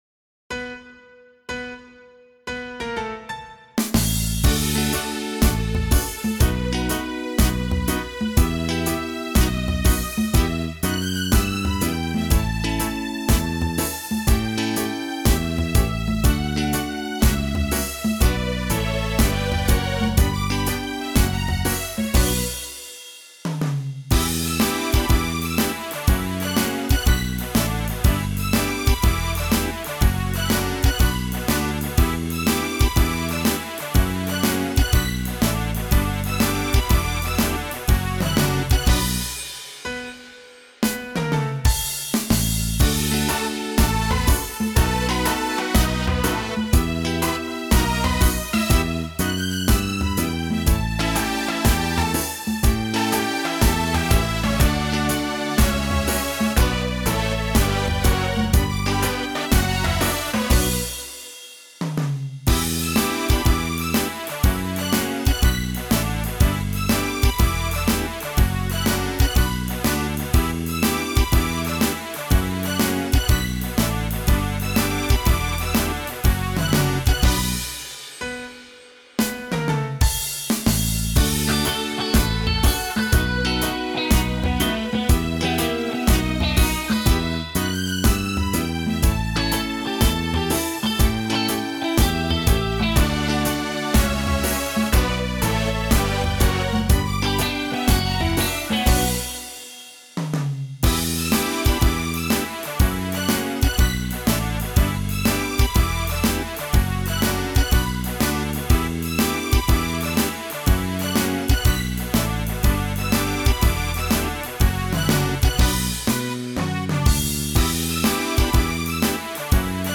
минусовка версия 48328